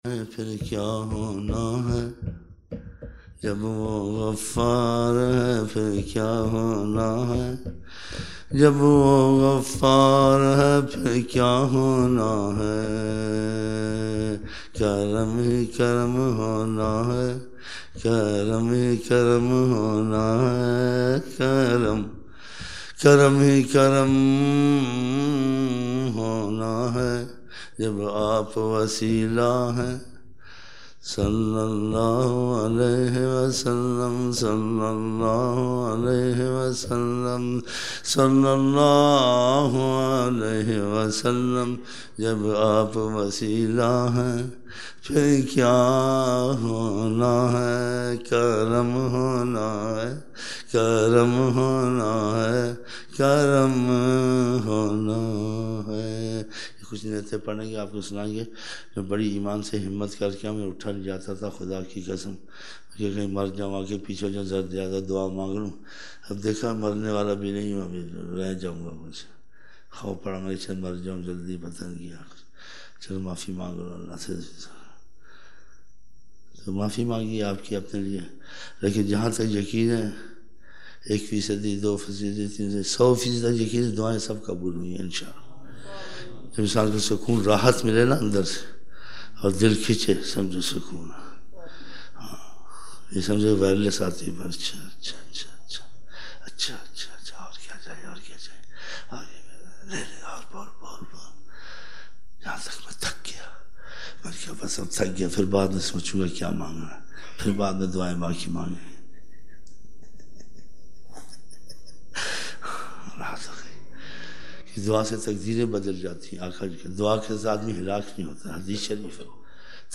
30 November 1999 - Fajar mehfil (20 Shaban 1420)